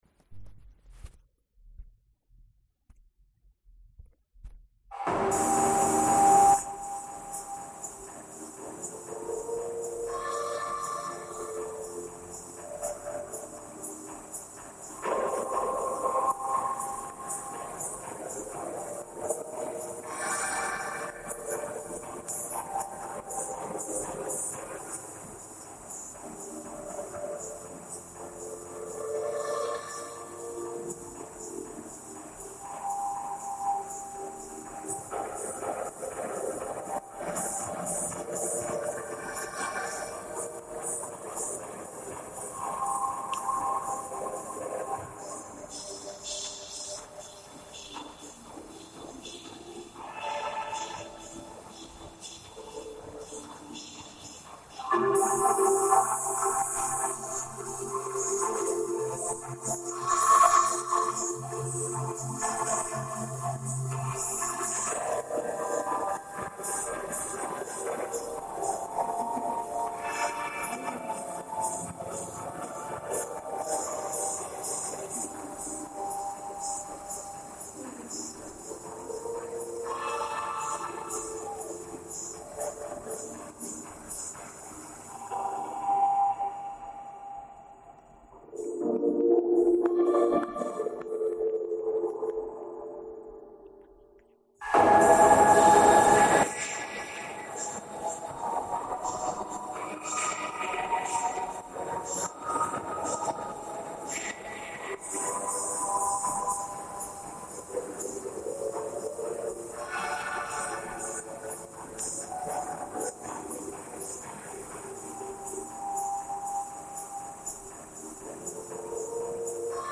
Кодек mp3 Битрейт 69 кбит/сек Частота дискретизации 44.1 КГц MD5 Windows 7 turns to Windows 8 Intro Effects (Sponsored By Preview 2 Effects) in reverb